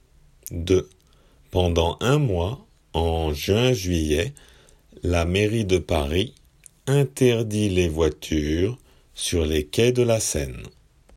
仏検受験用　聞き取り正誤問題－音声